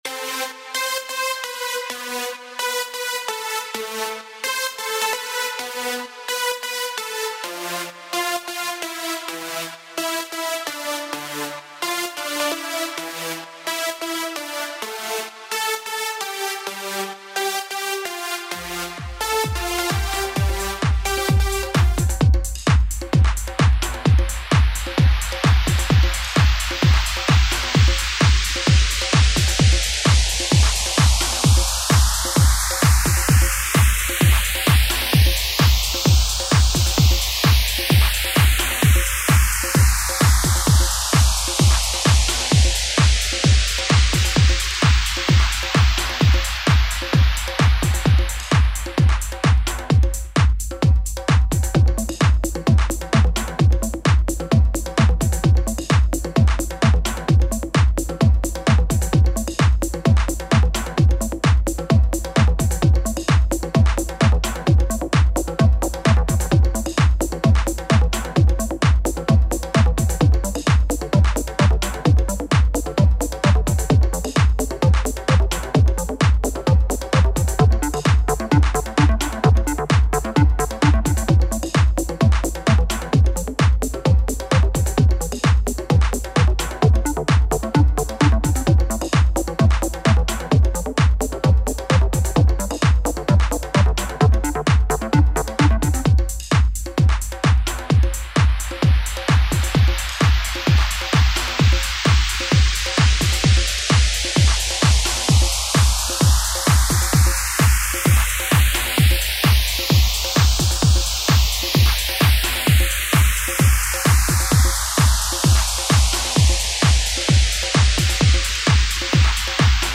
Home > Music > Electronic > Running > Chasing > Restless